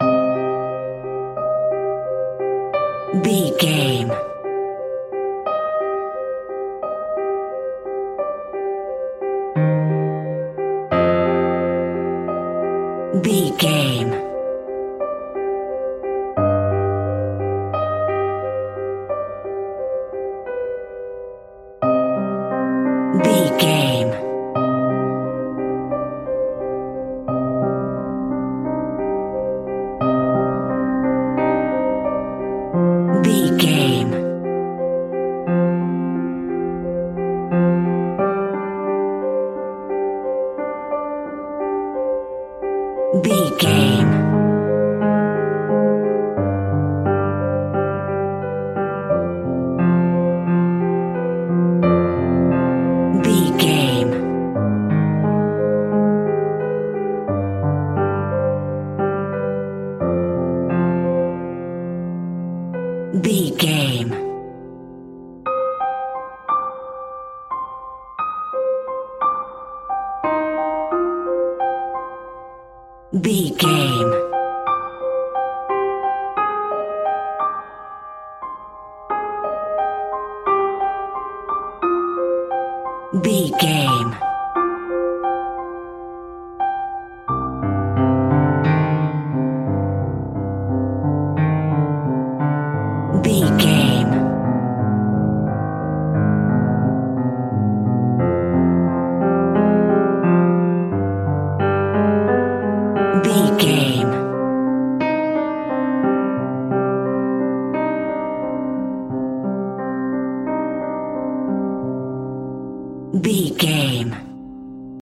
Piano Music for a Haunted Film.
Aeolian/Minor
ominous
suspense
eerie
Scary Piano